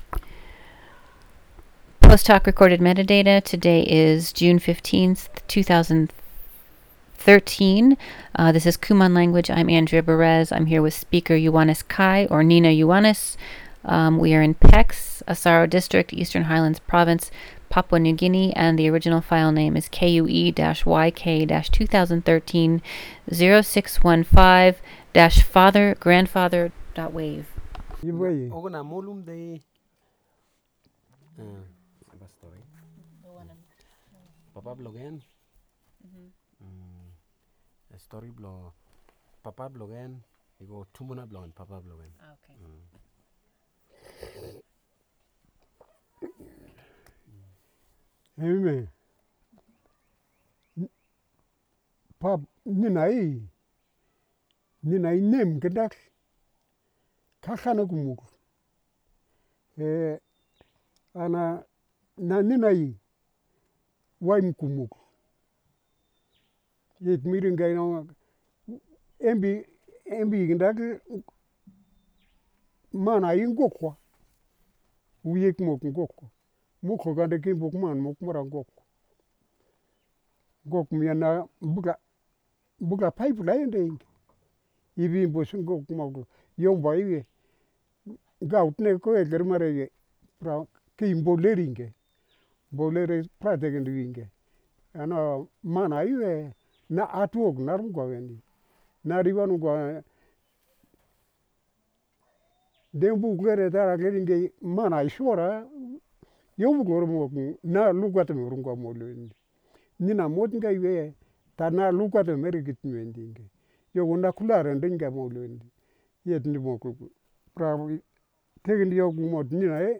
digital wav file recorded at 44.1 kHz/16 bit on Zoom H4n solid state recorder with Countryman e6 headset microphone
Pex Village, Asaro District, Eastern Highlands Province, Papua New Guinea